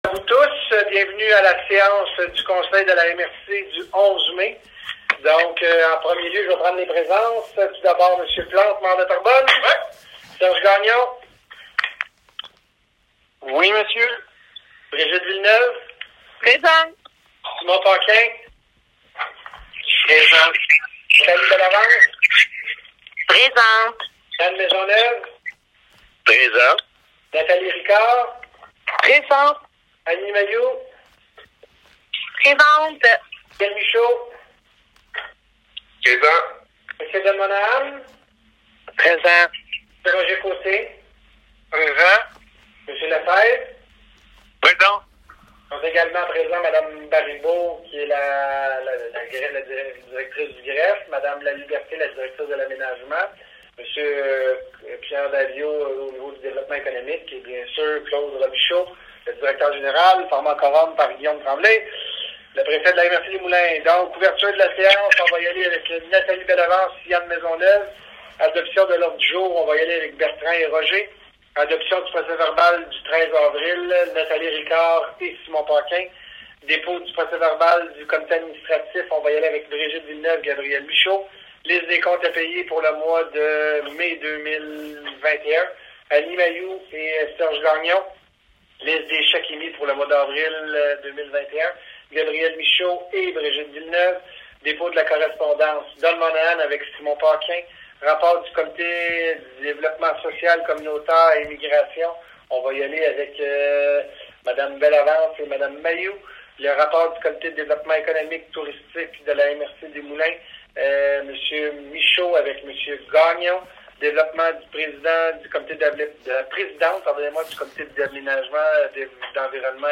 Enregistrements des séances à huis clos - MRC Les Moulins